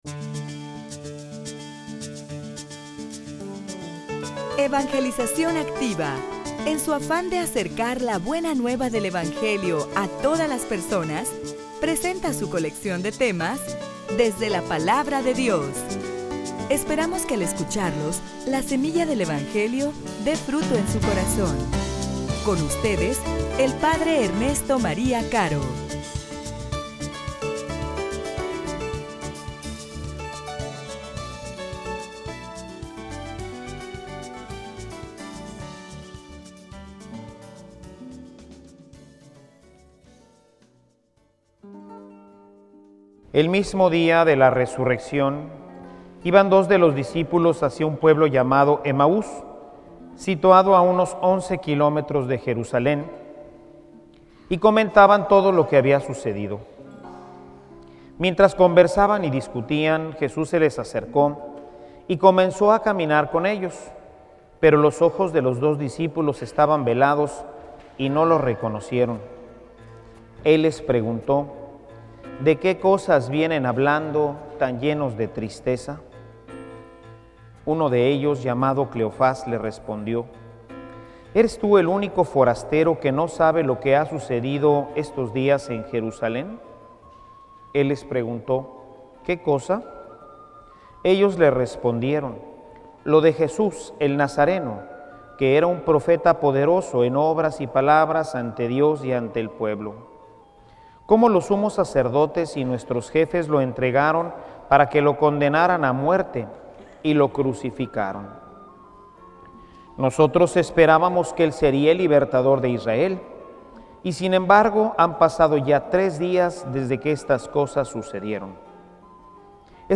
homilia_Que_efectos_produce_en_ti_la_Palabra.mp3